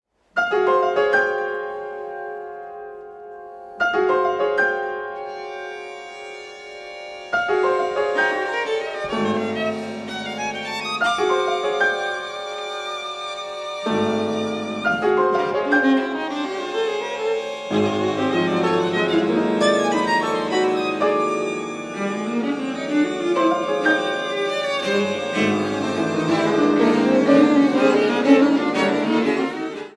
Beschreibung:Kammermusik; Klassik
(Konzertmitschnitt UA: Auszug aus dem 1. Satz)
Kammermusikabend